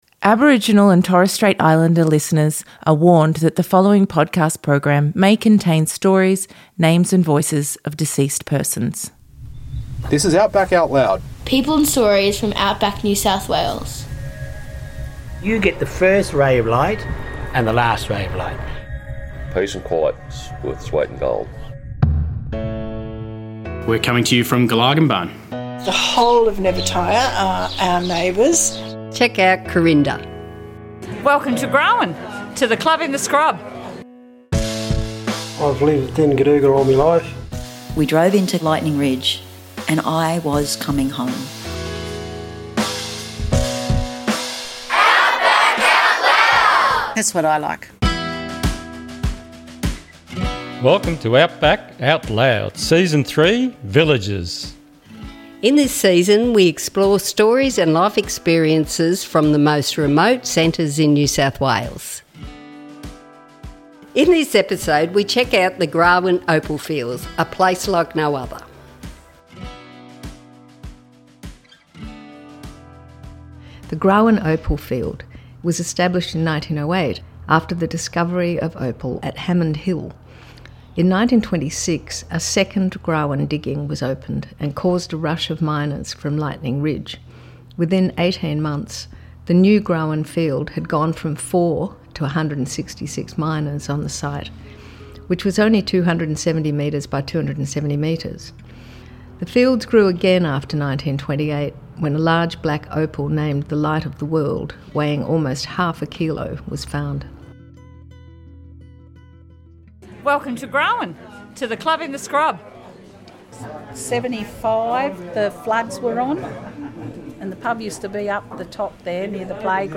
In this Episode we hear interviews from people in Grawin NSW featuring stories, memories and reflections about the Grawin area Opal Fields. This cluster of opal fields can be found in a remote area about 70 kilometres west of Lightning Ridge, accessed via the small village of Cumborah, 30 minutes northwest of Walgett.